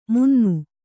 ♪ munnu